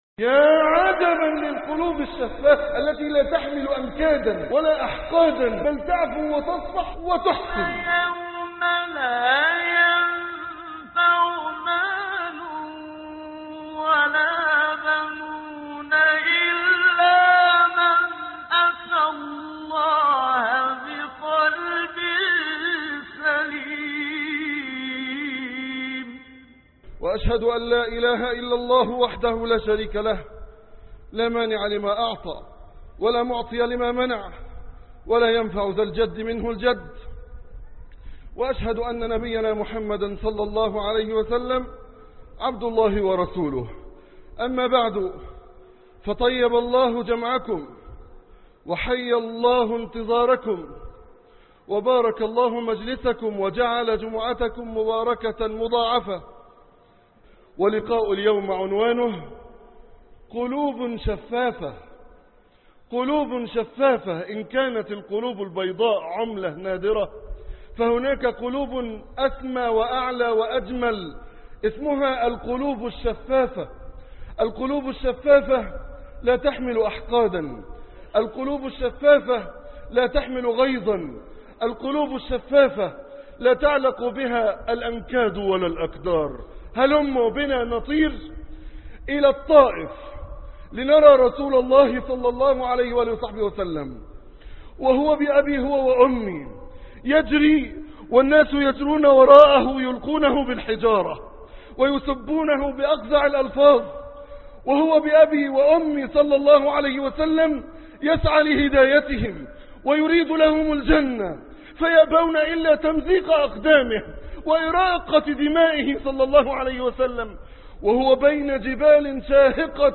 قلوب شفافة -خطب الجمعة